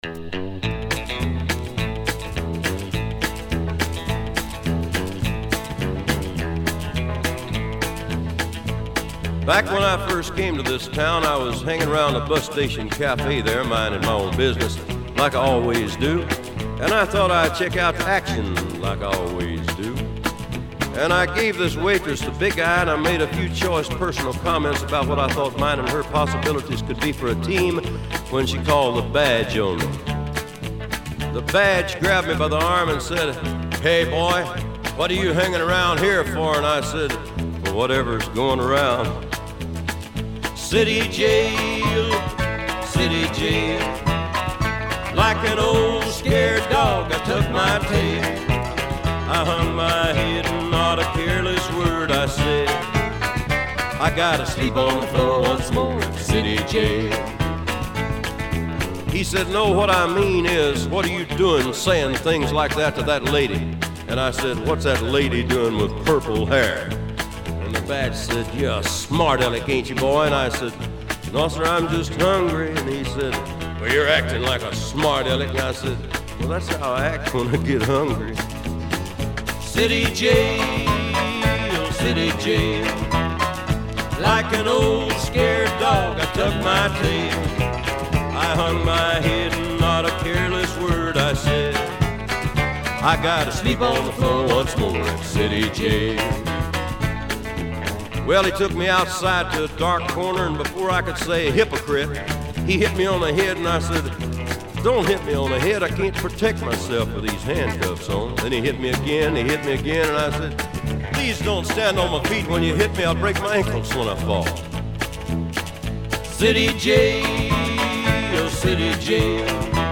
Жанр: Folk, World, & Country, Acoustic, Rock & Roll